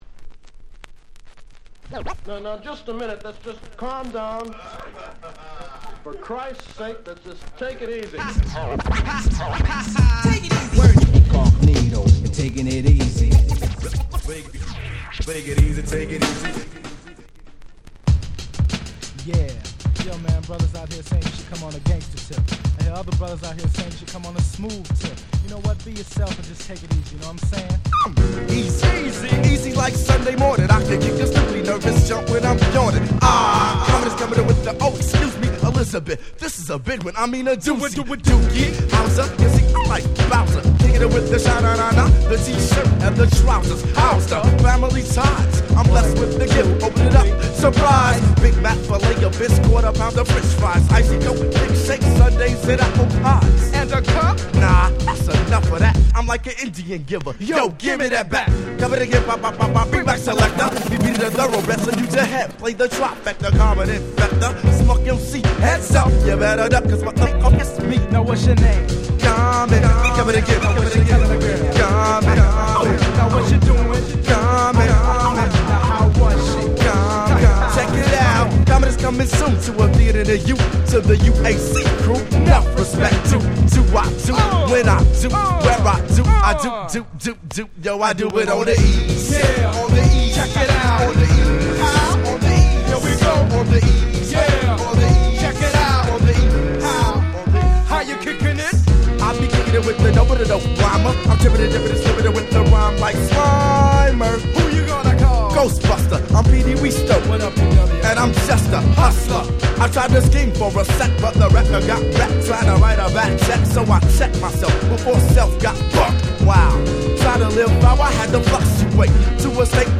92' Hip Hop Super Classics !!
90's Boom Bap ブーンバップ